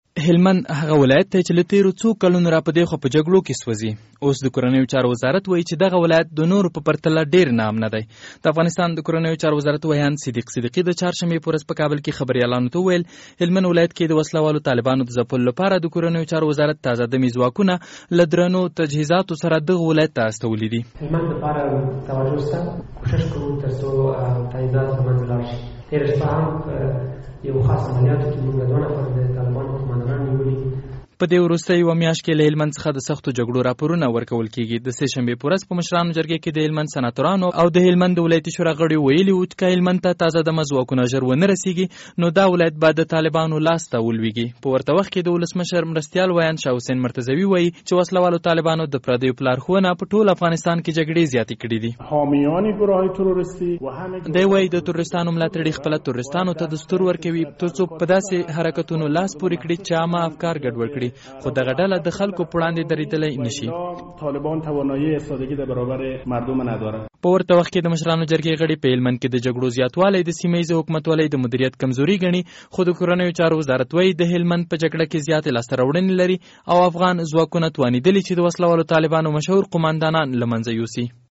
by ازادي راډیو